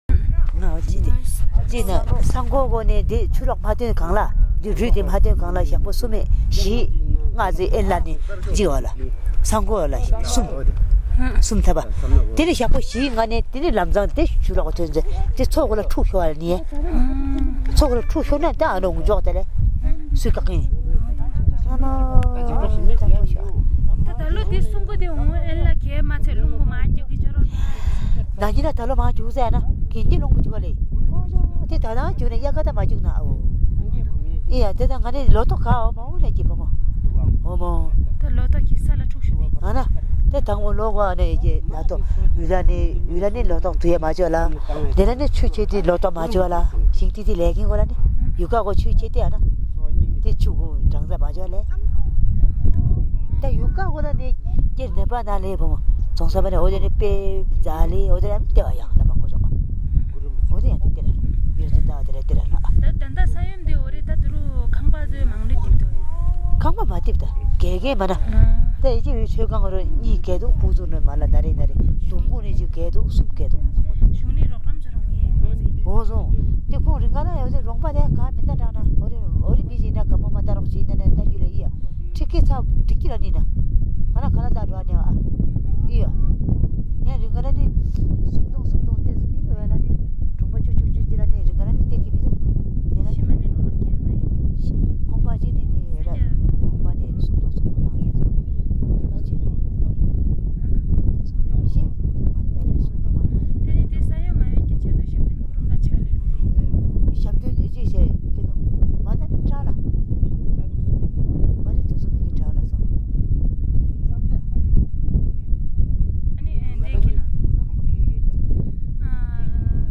Interview of a community member on the 2015 Nepal Earthquakes
Audio Interview